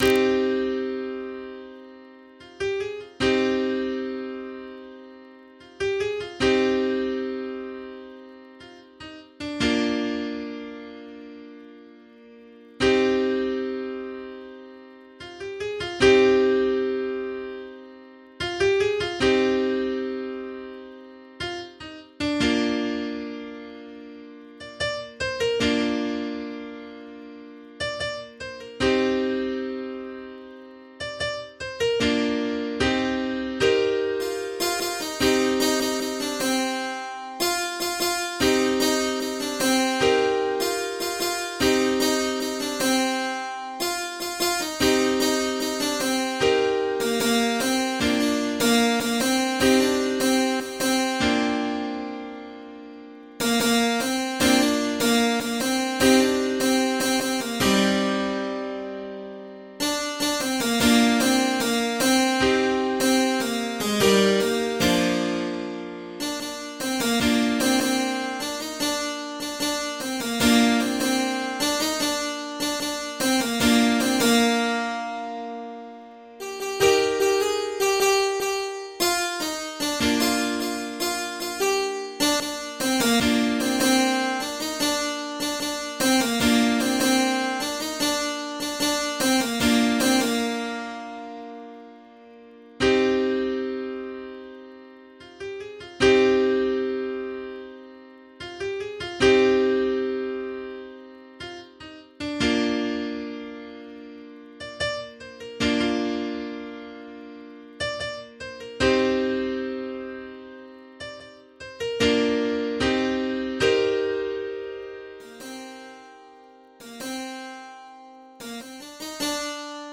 MIDI 8.32 KB MP3